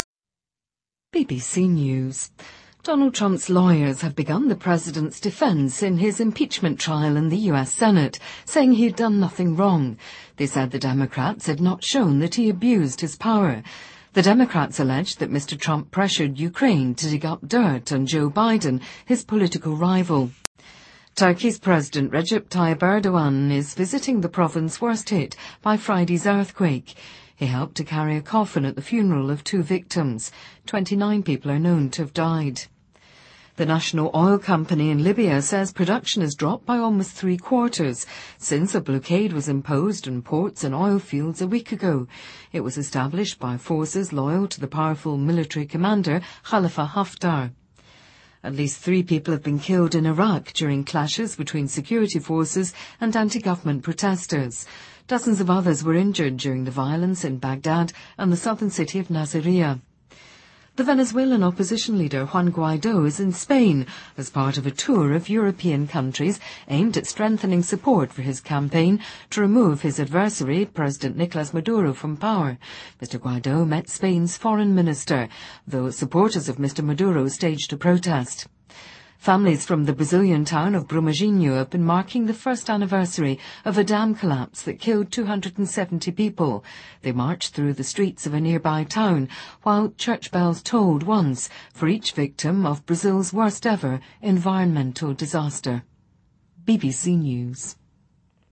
英音听力讲解:特朗普组建“明星律师团”